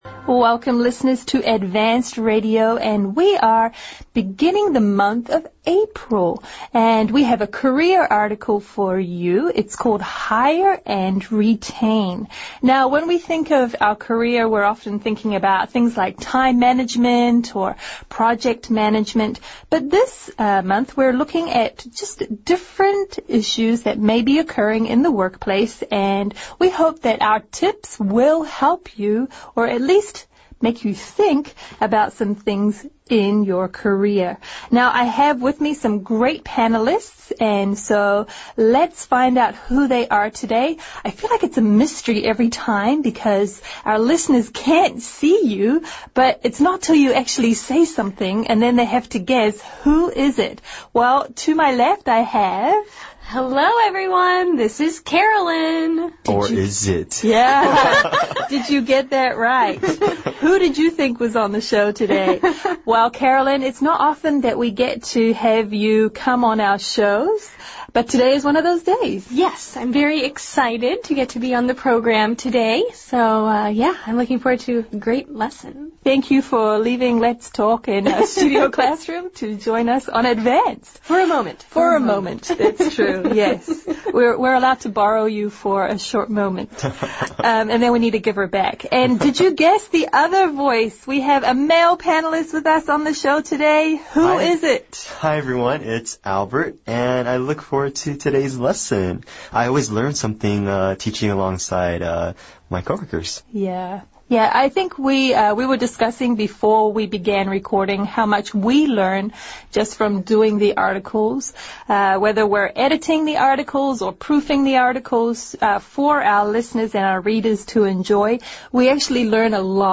《空中英语教室》主要以收录世界最新时尚资讯、热点话题、人物、故事、文化、社会现象等为主，以谈话聊天类型为主的英语教学节目。